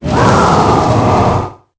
Cri_0851_EB.ogg